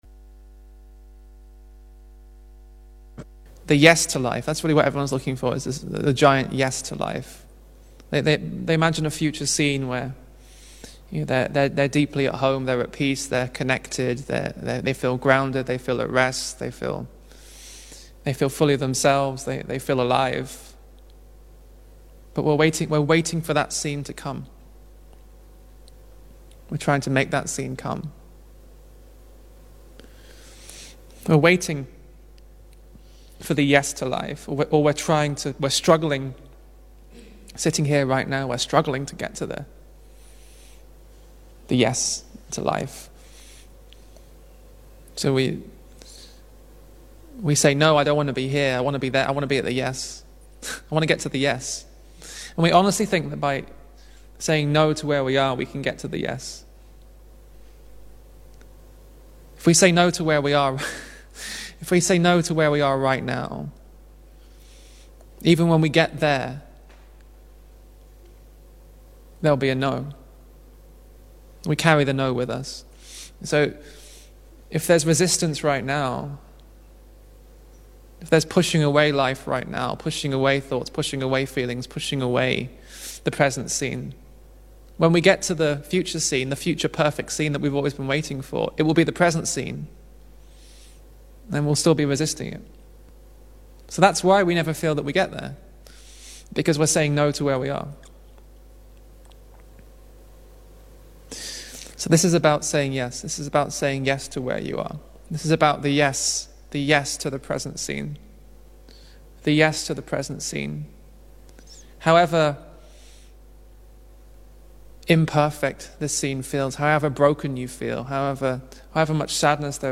Neviem, ako pre vás, ale mne tento päťminútový pokec Jeffa Fostera dal ohromne veľa.